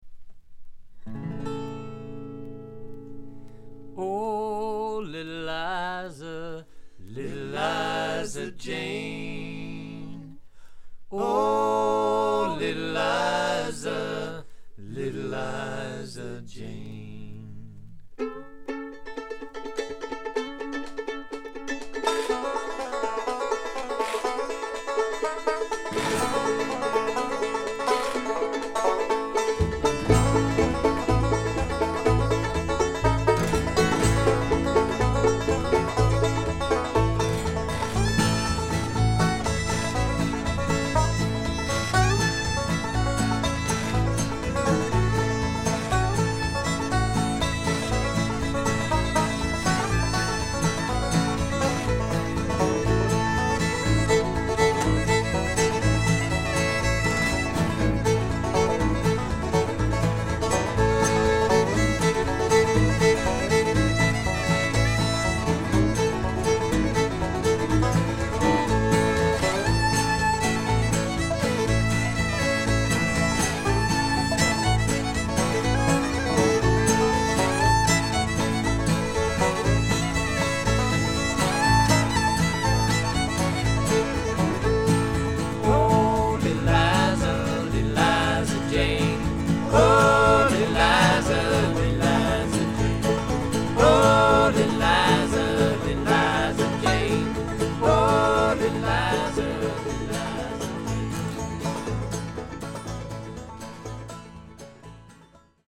With CB2, the piano and brass sounded more brilliant.
Bluegrass, male chorus